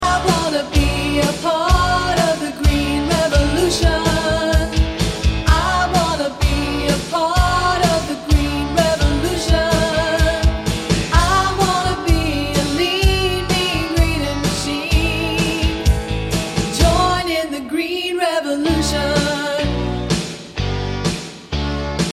A Nature Appreciation Song